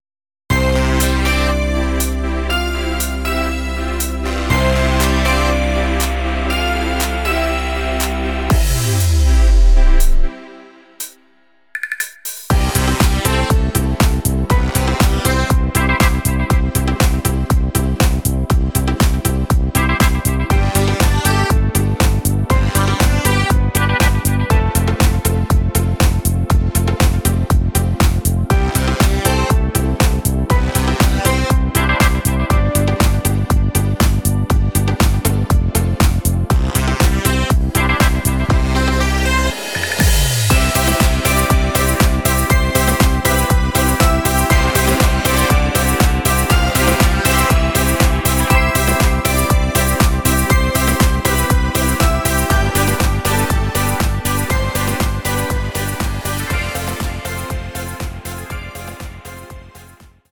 für den Sänger minus 5